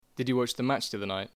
Examples of RP
//dɪdʒu wɒtʃ ðə mætʃ ði ˈʌðə naɪt//
Notice the pronunciation of Did you as /dɪdʒu/.
03_RP.mp3